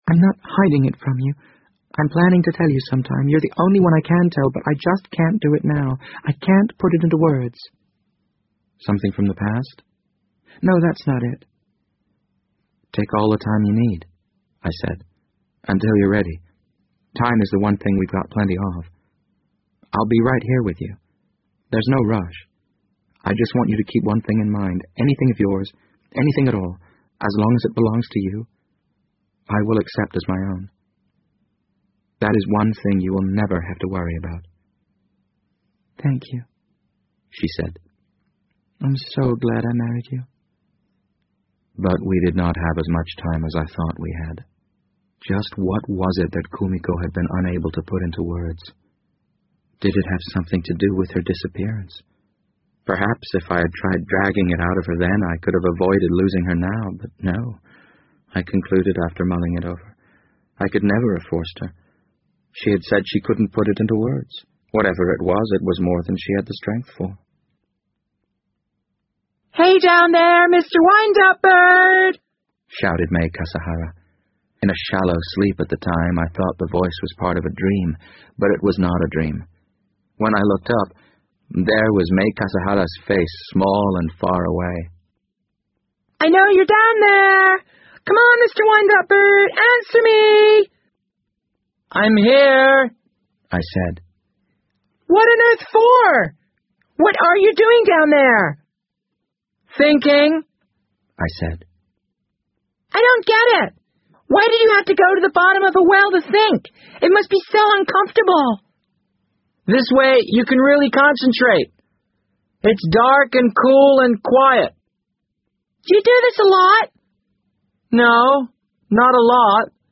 BBC英文广播剧在线听 The Wind Up Bird 007 - 4 听力文件下载—在线英语听力室